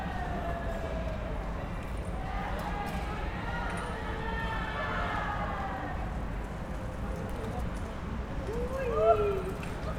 Environmental
UrbanSounds
Streetsounds
Noisepollution